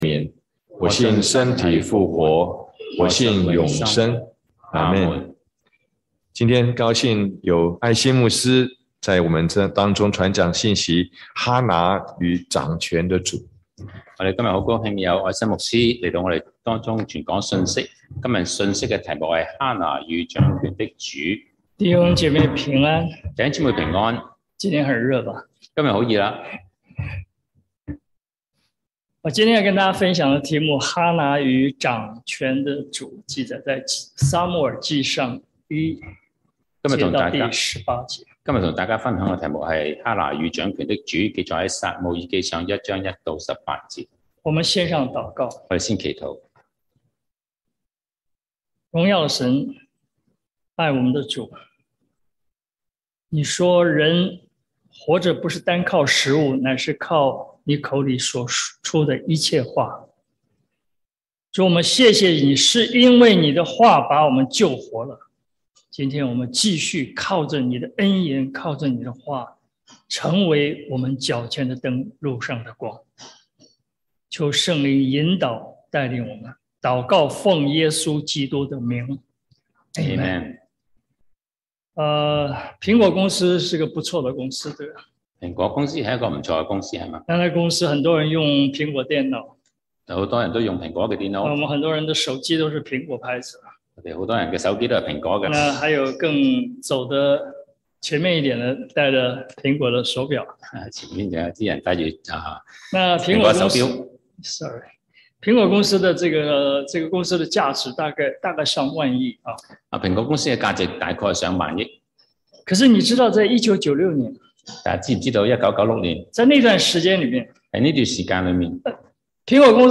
國語主講，粵語翻譯